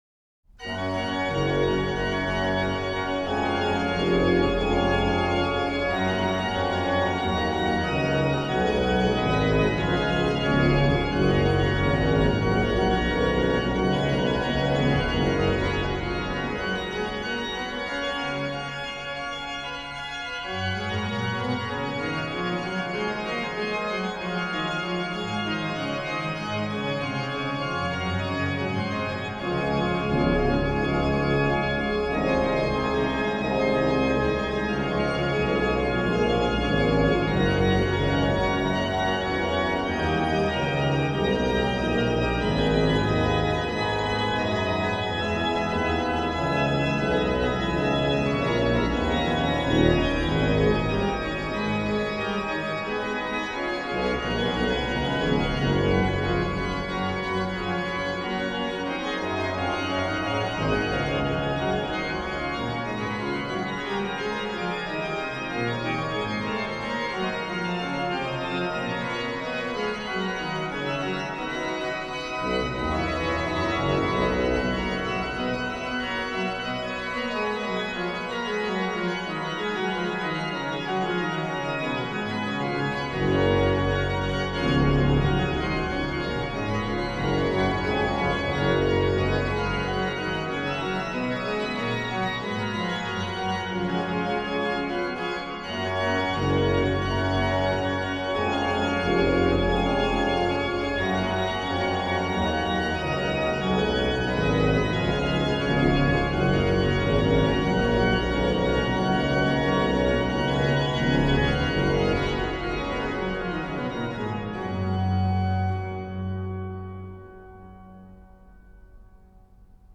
HW: Pr8, Oct4, Oct2, Zim
Ped: Oct8, Oct4, Pos16